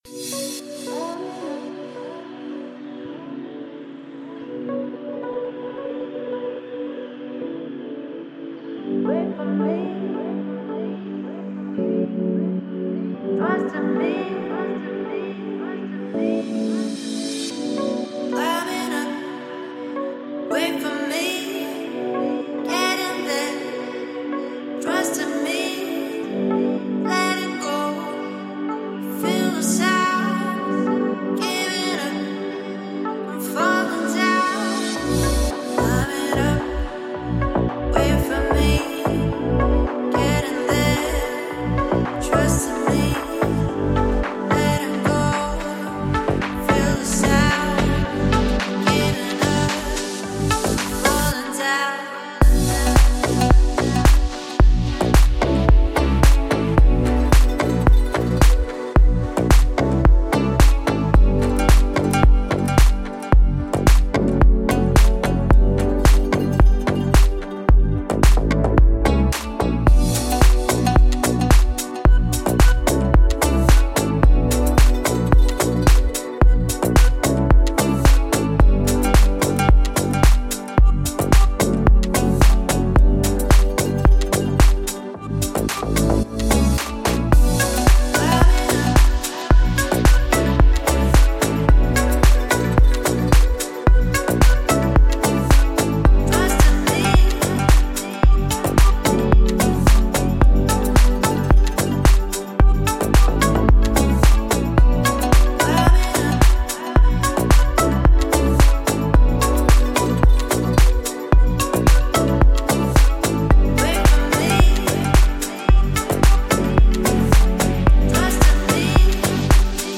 который сочетает в себе элементы поп и электронной музыки.